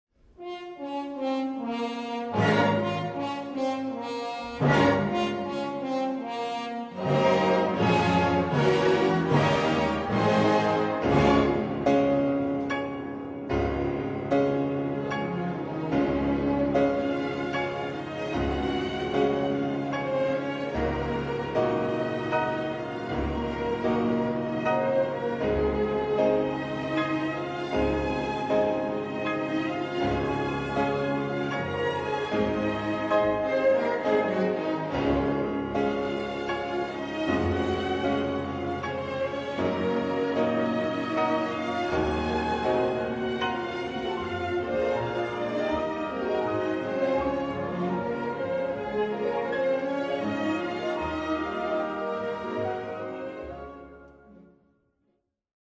Melodie romantiche
La melodia si esprime in contrasti dinamici forti, a tratti violenti: anche il suo profilo appare più irregolare, distante dalla compostezza razionale del pensiero illuminista. Ascolta questo frammento del Concerto per pianoforte e orchestra di Piotr Ilic Ciaikovsky (1840-1893): ciaikovsky_concerto_per_piano.mp3